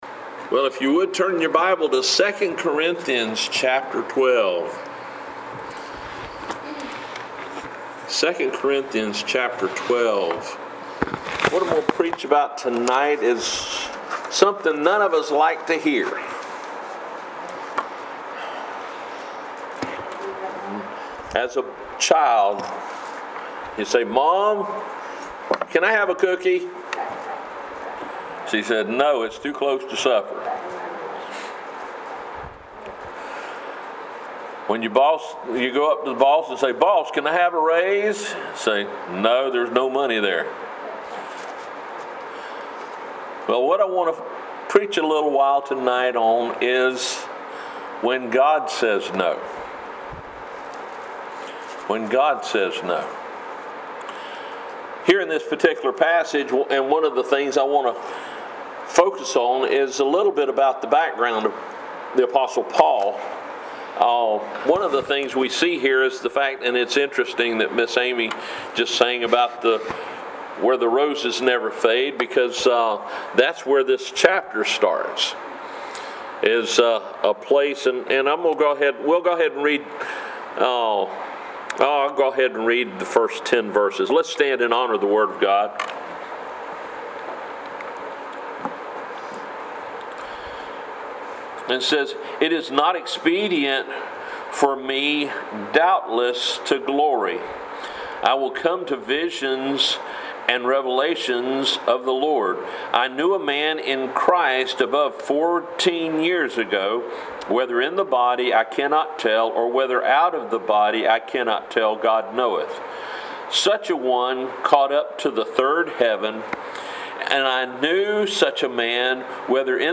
4/1/2020 Wednesday Service